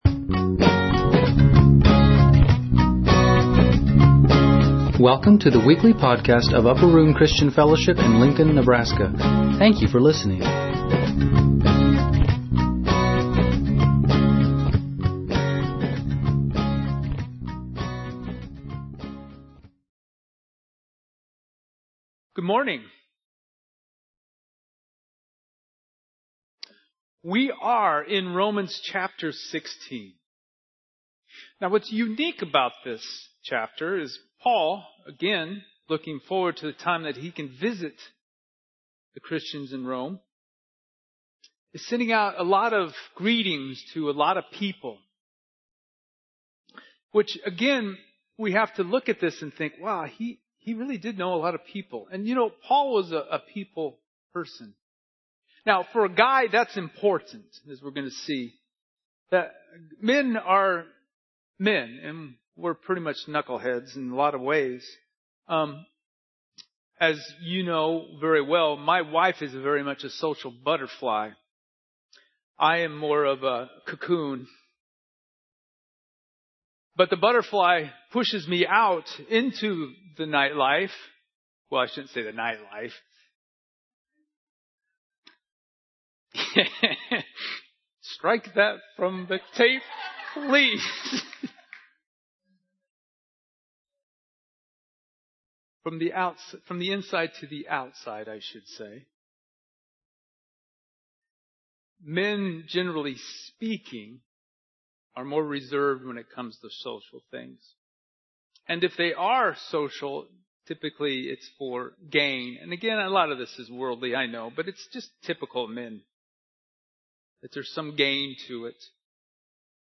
There is a change in sound quality starting midway through the sermon due to technical difficulties.